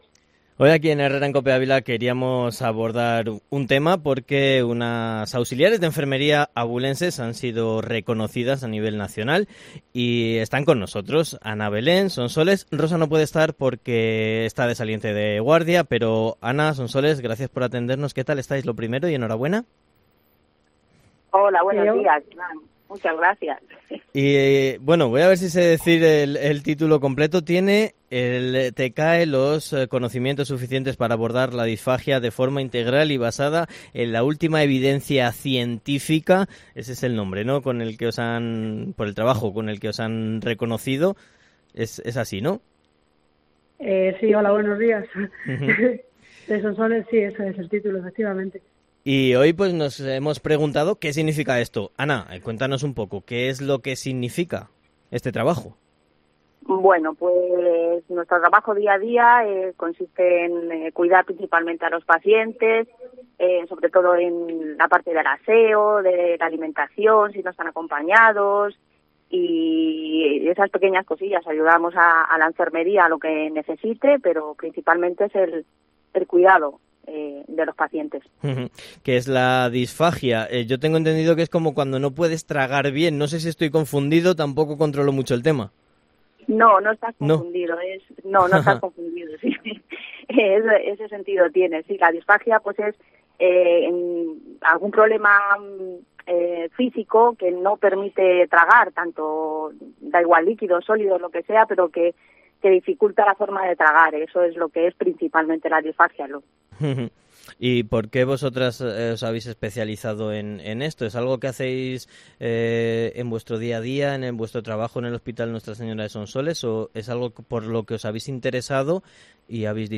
Entrevista-auxiliares-enfermeria en COPE